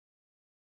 BoingBoing told me that the latest version of iTunes spys on what you listen to. In response I've made this .666 seconds of silence mp3 titled